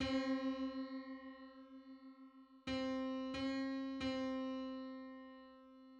Diesis on C
Diesis as three just major thirds.
Diesis (128:125) demonstration
The octave C-C′, the three justly tuned major thirds C-E-G-B and the descending diesis C′-B are played (see example).
Diesis_on_C.mid.mp3